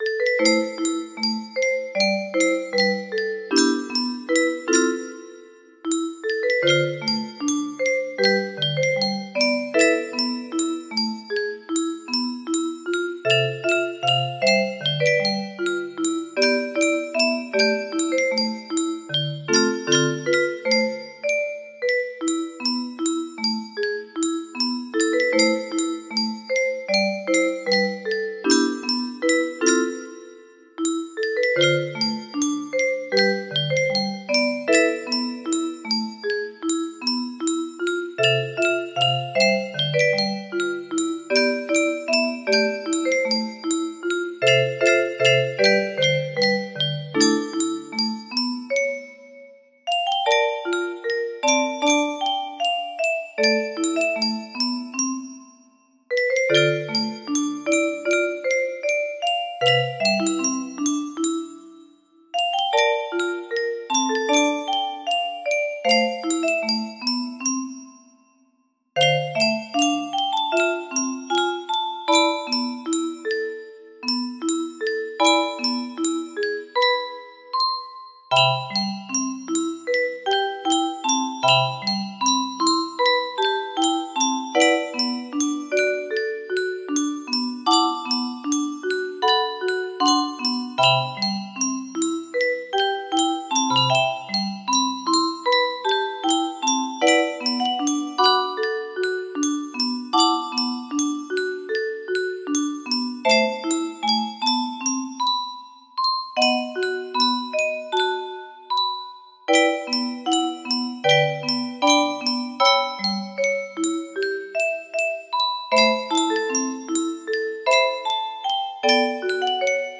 sd4 music box melody